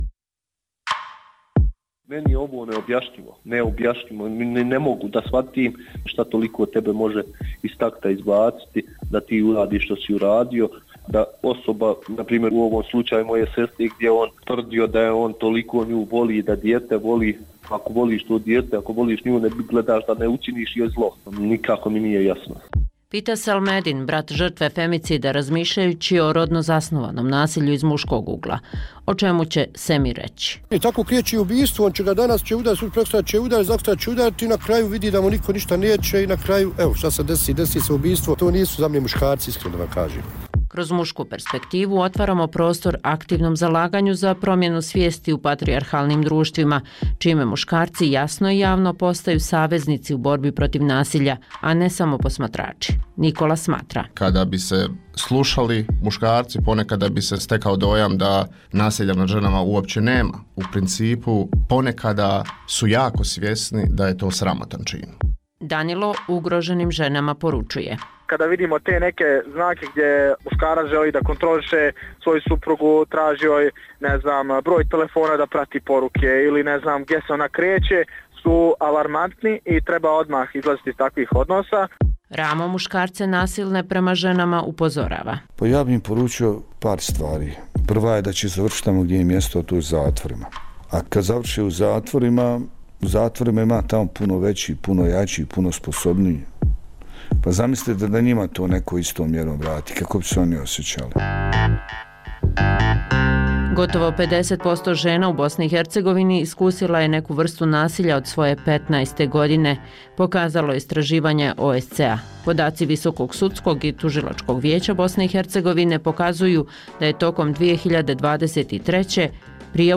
U Mostu Radija Slobodna Evropa razgovaralo se o tome koji su putevi izlaska iz duboke krize u kojoj se nalazi Srbija.